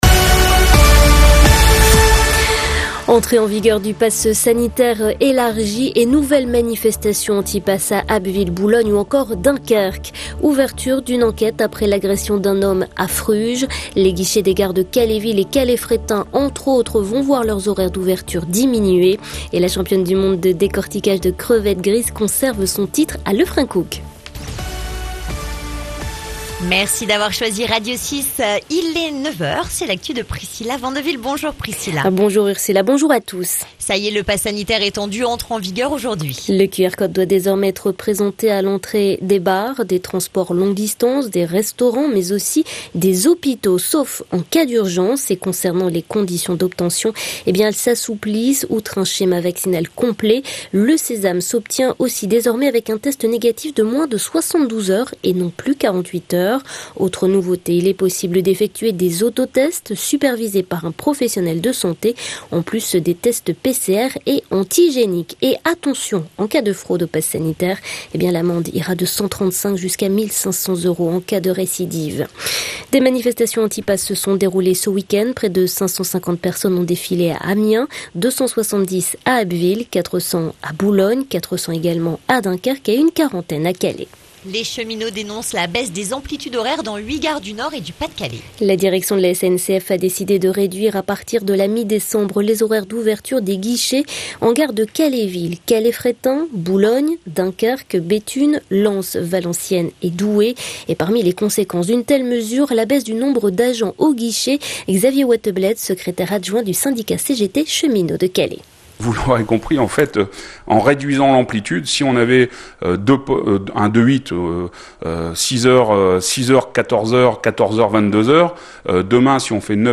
Le journal du lundi 9 août